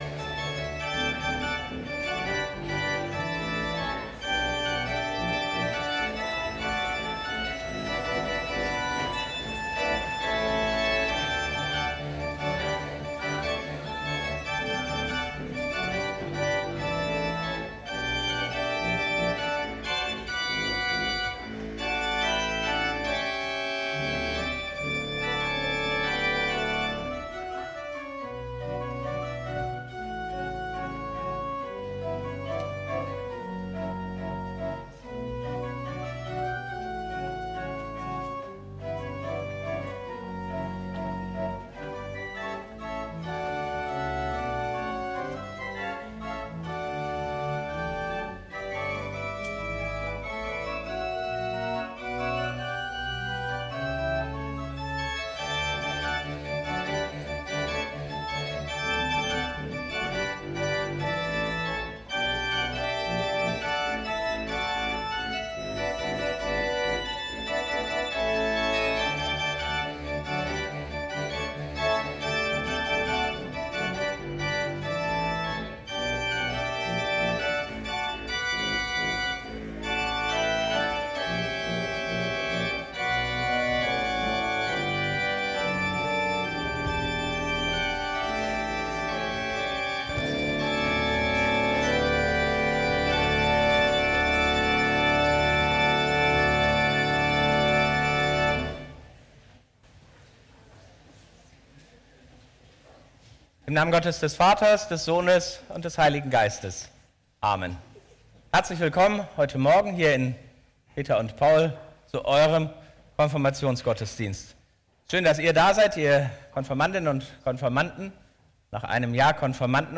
Gottesdienst - 25.05.2025 ~ Peter und Paul Gottesdienst-Podcast Podcast